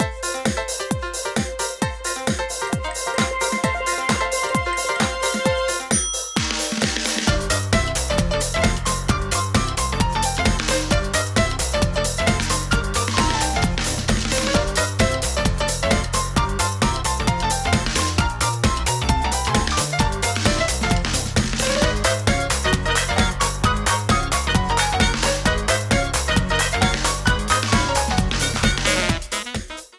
Frontrunning music